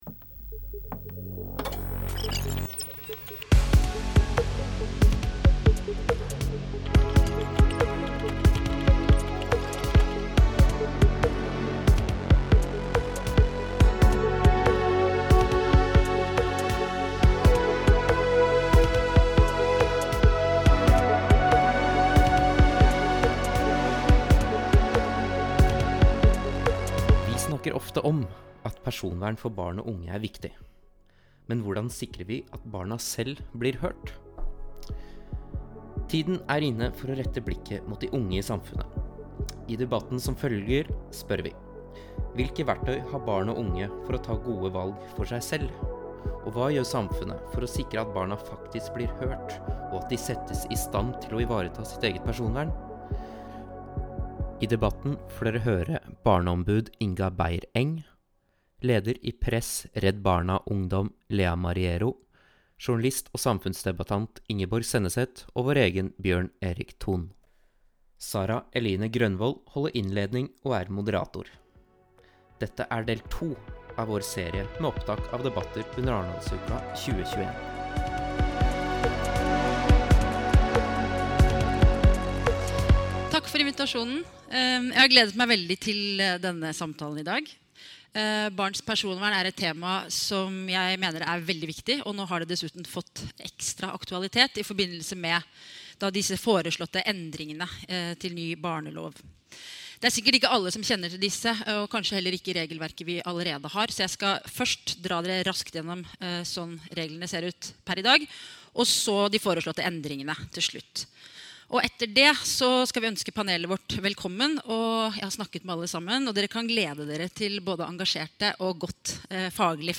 Dette er et opptak fra et av våre arrangementer under Arendalsuka 2021.